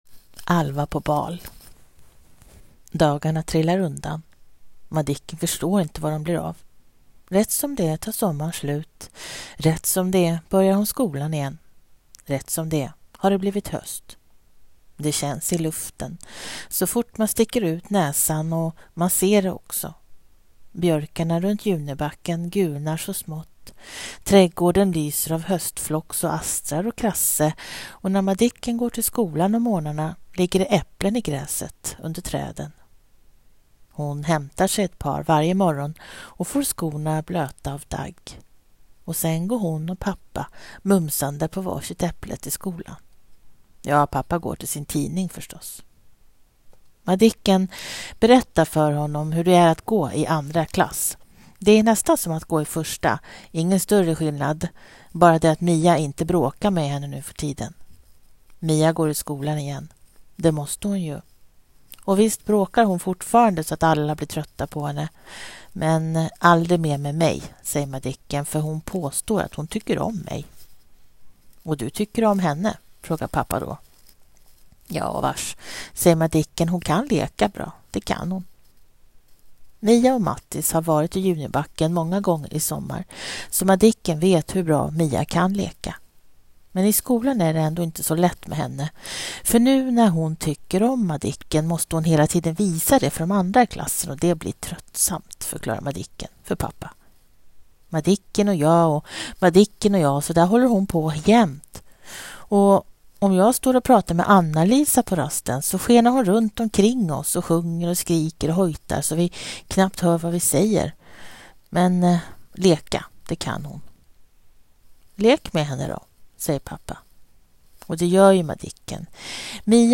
Här läser jag in den, på sidan i sängen med en halvdålig mikrofon.